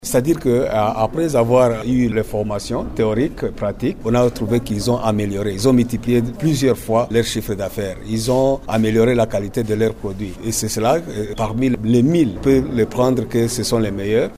La cérémonie de leur décoration a été organisée le vendredi 24 mai 2024 dans la salle de conférence de l’Ecole Enfant Internationale du Monde situé sur avenue Résidence dans la commune d’IBANDA.